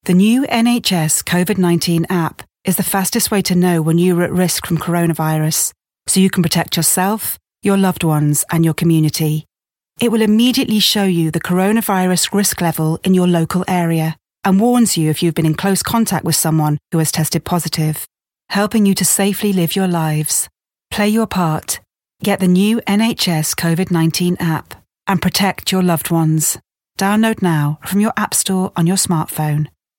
40's Northern,
Reassuring/Warm/Natural
• Commercial